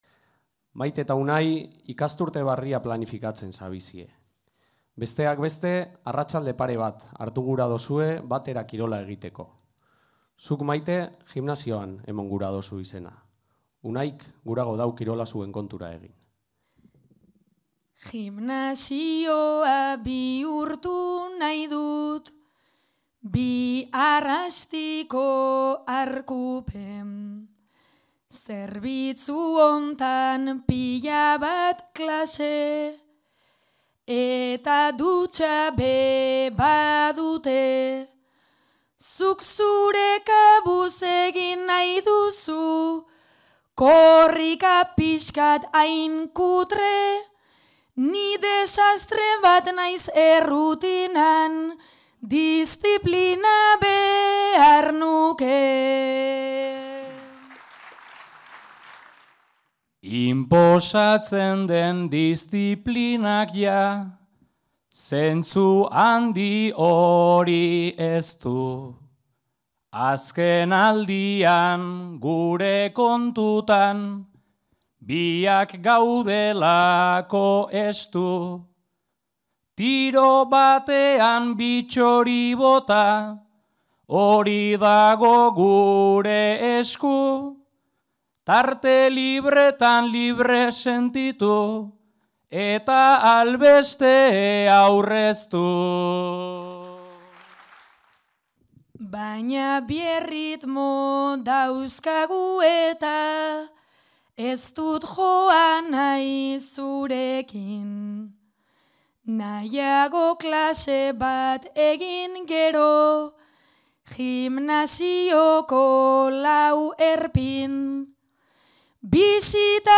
"Itzal(iko) bagina" emanaldia
Mungia (Bizkaia)
0:04:36 Zortziko handia.